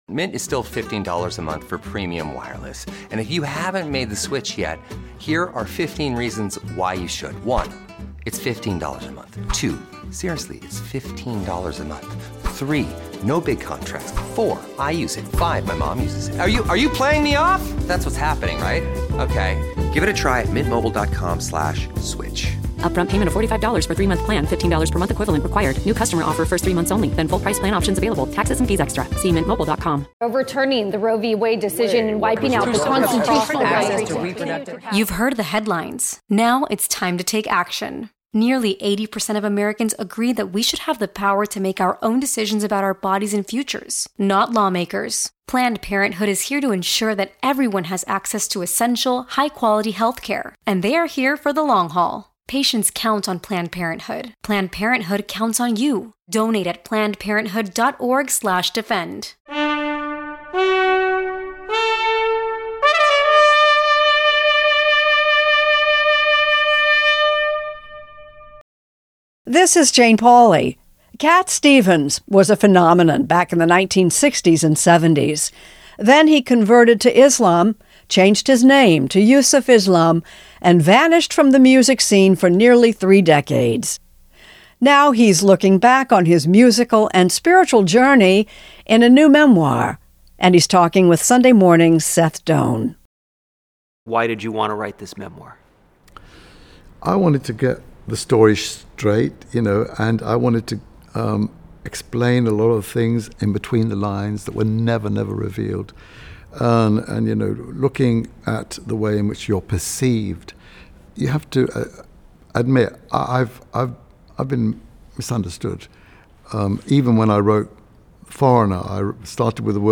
Extended Interview: Yusuf/Cat Stevens